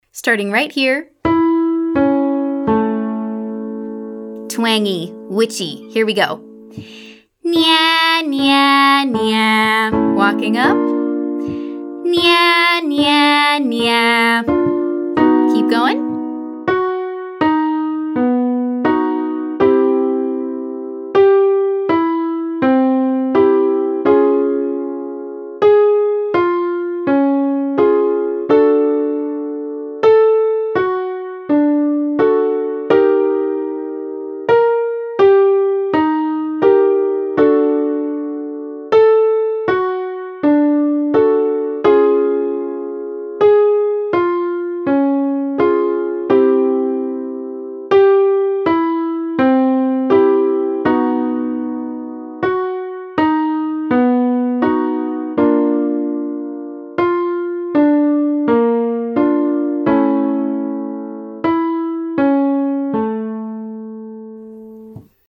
Exercise: NYEAH (5-3-1); may repeat with less twang
Pop Course Day 4 Exercise 1